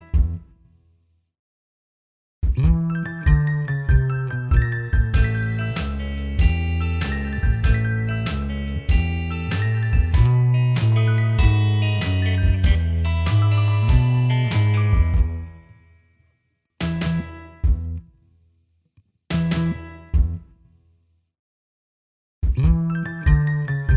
Minus Lead Guitar Pop (1960s) 2:11 Buy £1.50